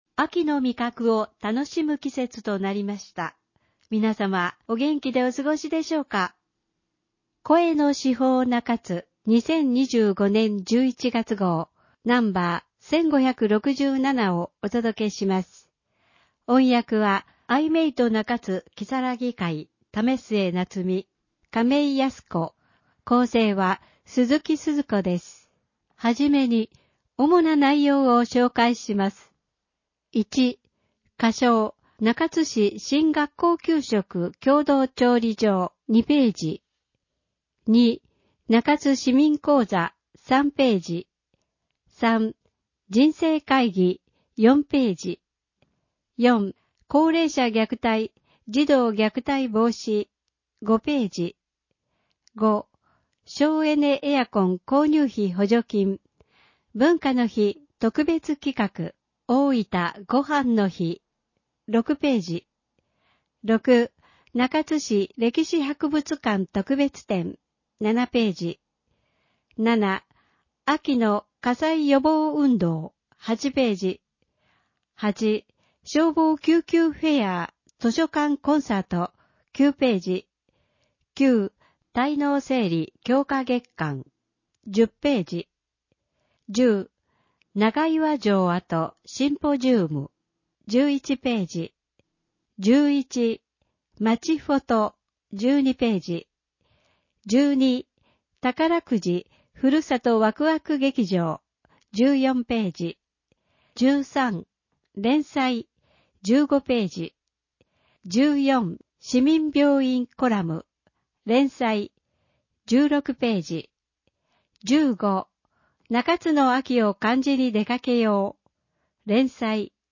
市報の内容を音声で聞くことができます。 アイメイト中津きさらぎ会がボランティアで製作しています。